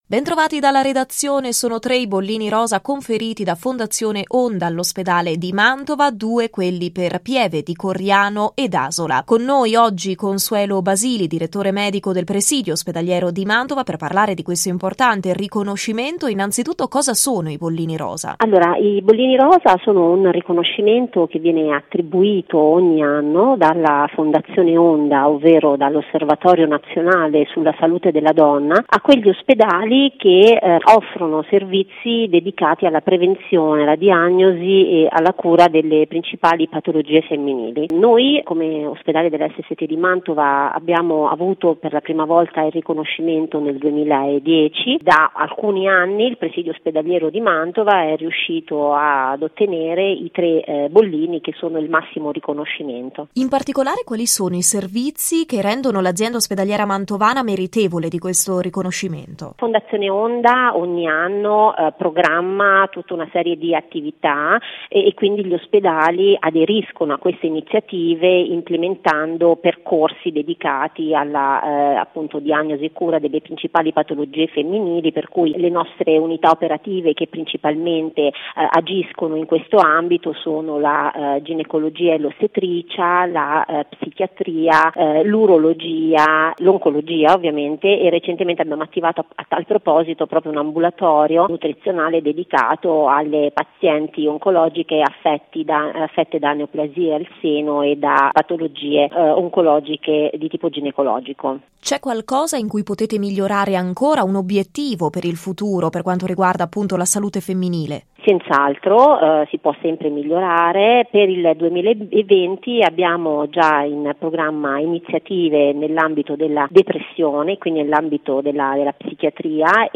Al nostro microfono